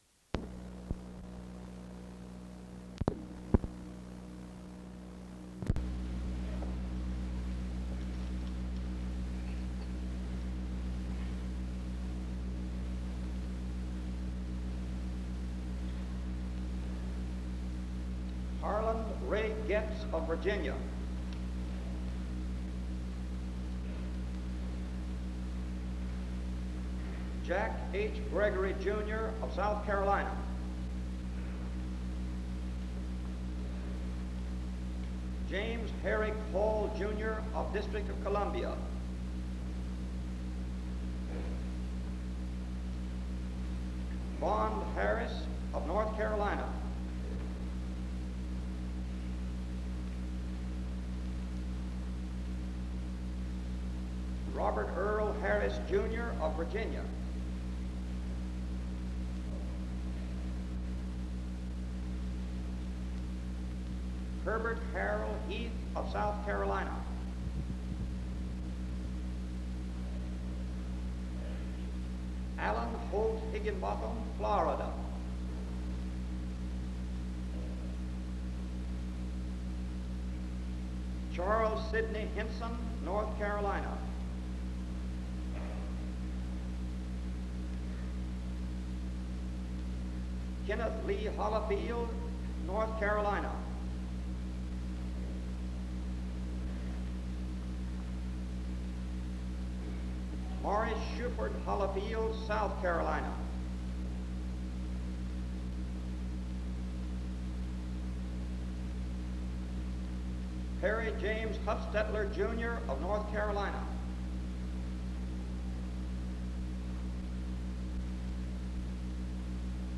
On Friday, May 16, 1958, the graduation program was given in the SEBTS Chapel.
[The recording ends at this point due to the rest having being inadvertently overwritten at some point before digitization.]
Commencement ceremonies